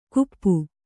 ♪ kuppu